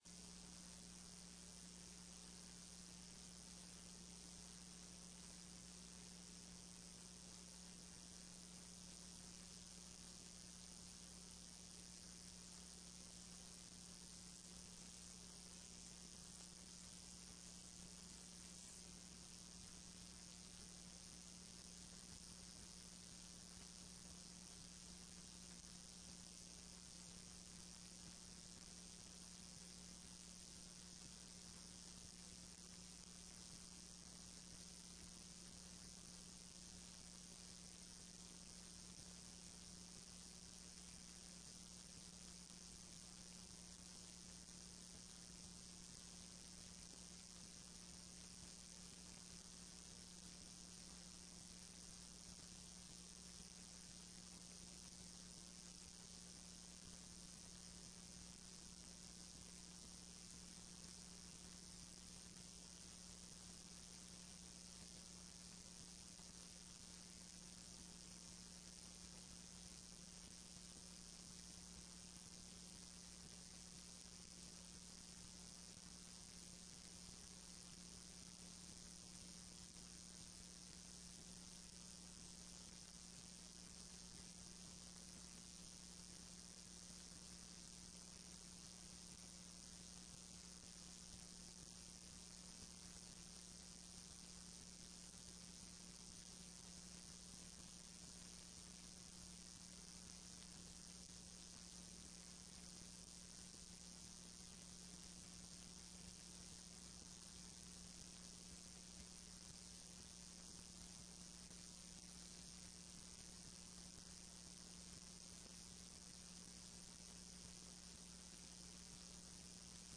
Sessão plenária do dia 23/02/15 do TRE-ES